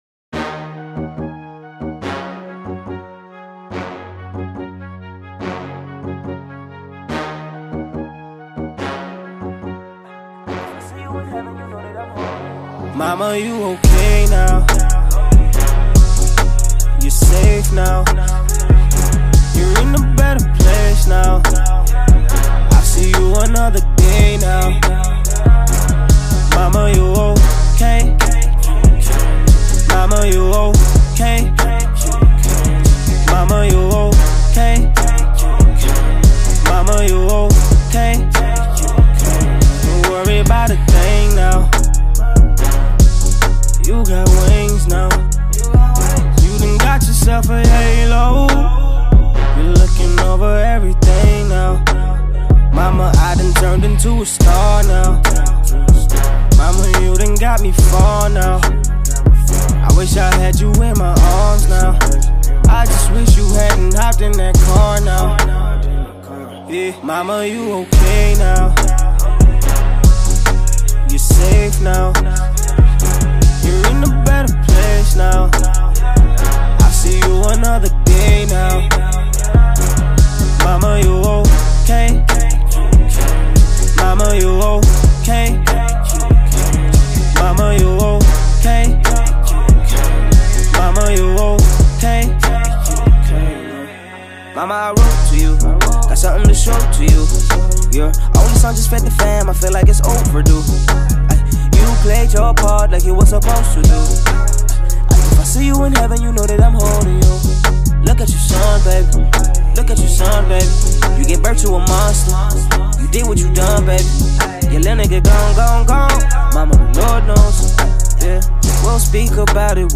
renowned South African rapper
Hip Hop